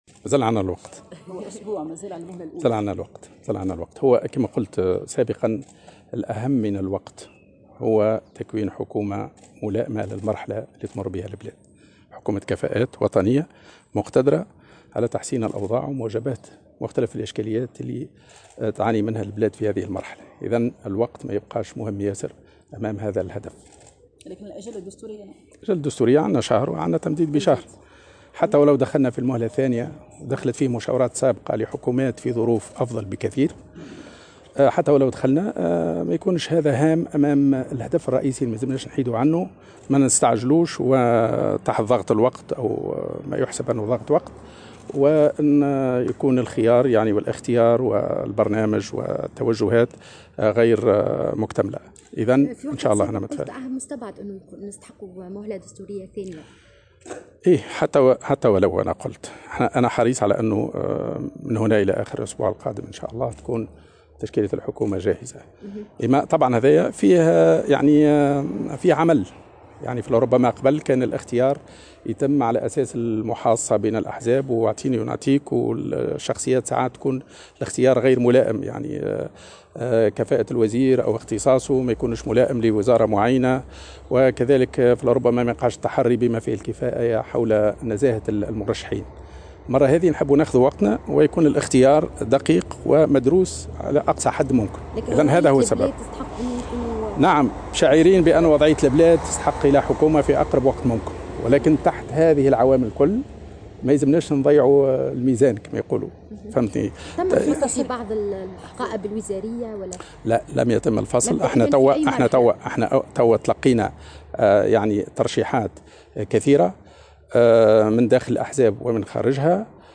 قال رئيس الحكومة المكلف الحبيب الجملي في تصريح لمراسلة الجوهرة "اف ام" إنه مازال يملك الوقت الكافي لتشكيل الحكومة معتبرا أن تكوين حكومة كفاءات وطنية ملائمة للمرحلة التي تمر بها البلاد أهم من ضغط الوقت.